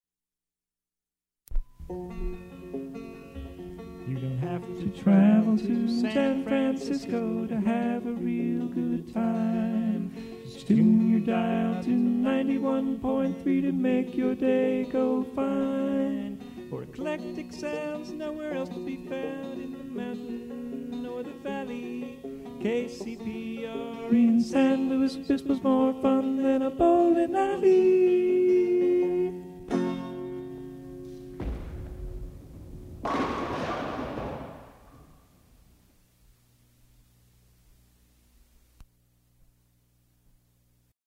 Station identification performed as a song
who is singing and playing banjo
Form of original Audiocassette